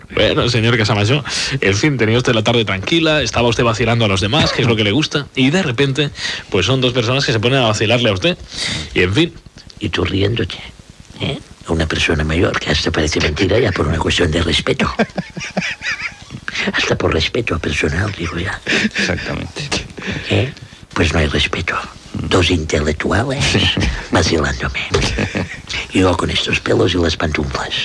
Entreteniment
Senyor Casamajor (Xavier Sardà)